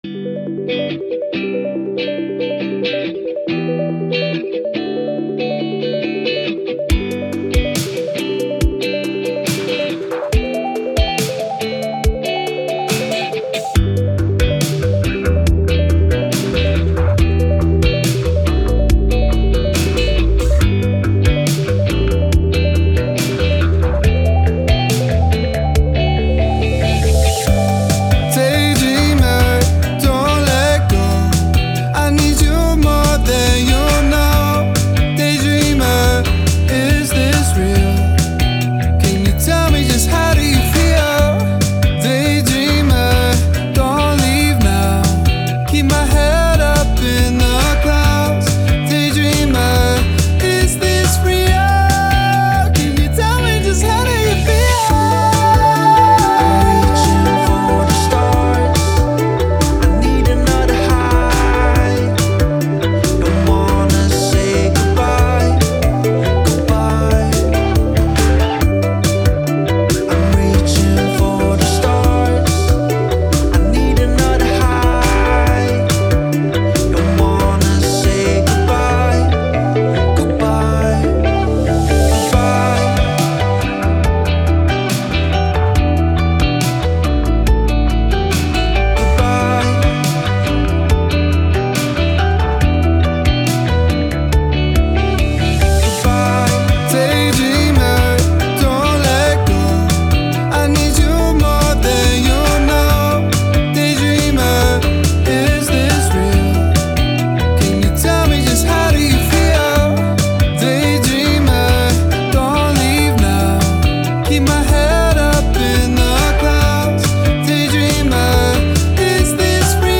Pop, Vocal, Thoughtful, Indie